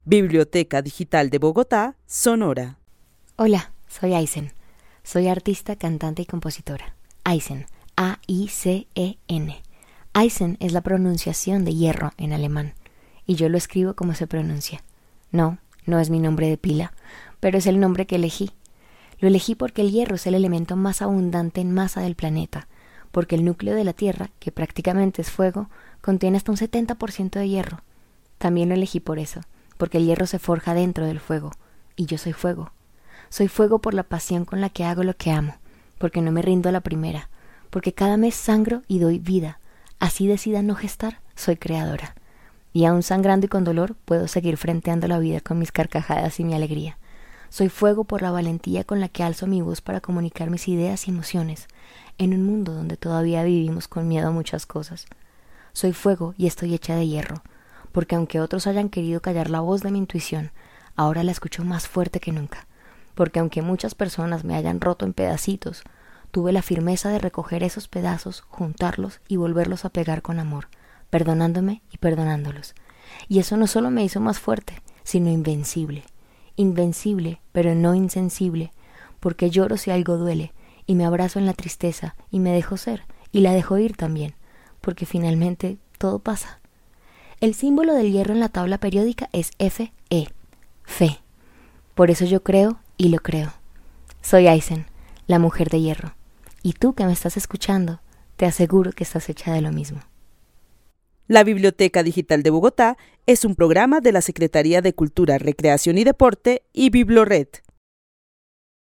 Narración oral de una mujer artista, cantante y compositora que vive en la ciudad de Bogotá. Desde su experiencia de vida, para ella ser mujer es ser fuego por la pasión con la que realiza las cosas que ama, porque no se rinde ante lo obstáculos y porque aunque decida no gestar siempre es creadora.
El testimonio fue recolectado en el marco del laboratorio de co-creación "Postales sonoras: mujeres escuchando mujeres" de la línea Cultura Digital e Innovación de la Red Distrital de Bibliotecas Públicas de Bogotá - BibloRed.